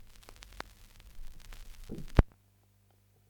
こちらの音声はA面の音楽が終わってレコード針が上がったときのノイズ音です。
A面の音楽が終わってレコード針が上がったときのノイズ音
end-part-noise-sample01.mp3